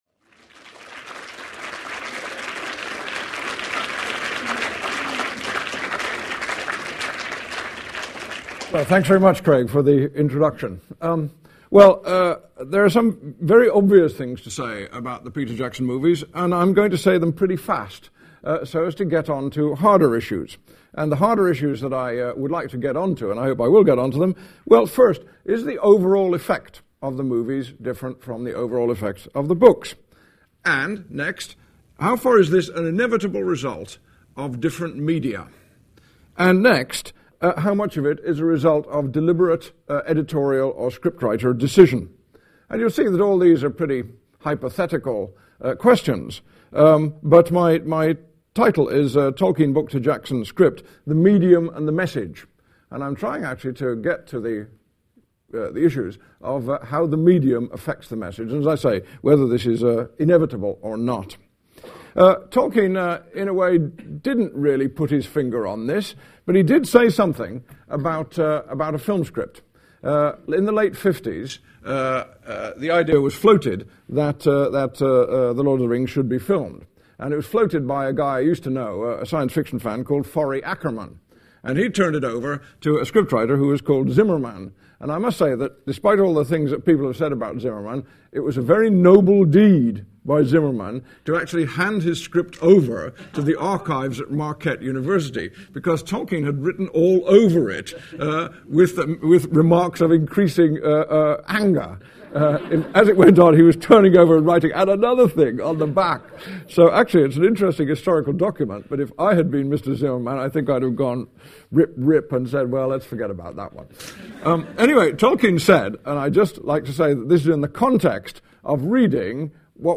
In this talk, Tom Shippey, the world’s foremost Tolkien scholar, charts the creative reshaping of Tolkien’s The Lord of the Rings into Peter Jackson’s award-winning trilogy of films. Professor Shippey was the literary consultant to Jackson and the actors as they worked on the films.